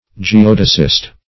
Geodesist \Ge*od"e*sist\, n. One versed in geodesy.